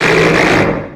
Cri de Téraclope dans Pokémon X et Y.